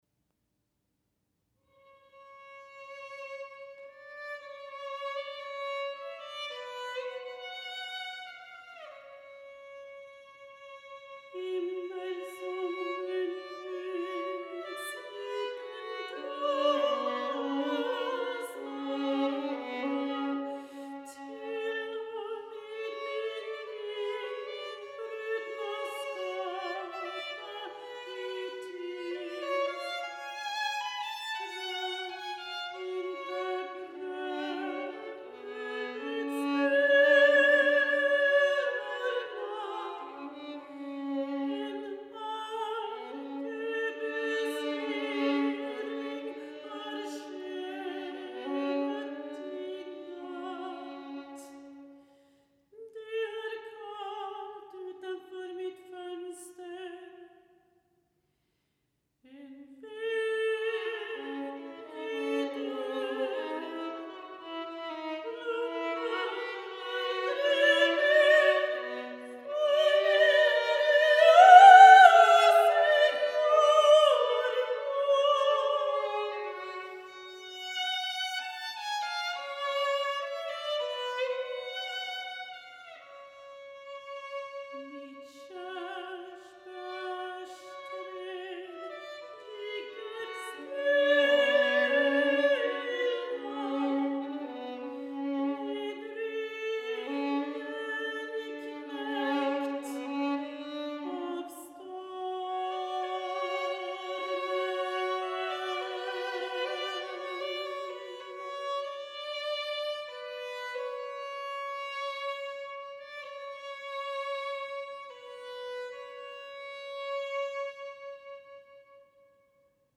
Mezzosopran